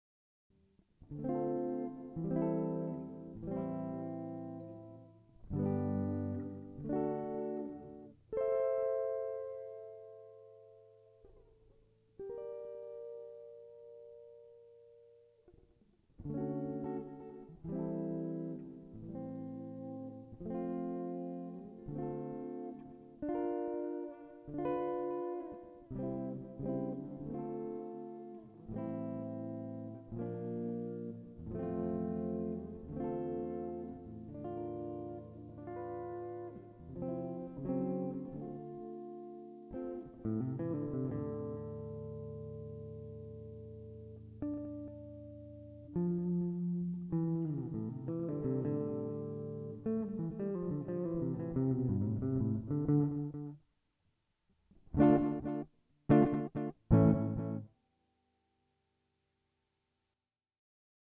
I was able to get a better sounding reverb in general, although more work needs to be done in order for the effect to pass the requirements.
short demo of the effect, and the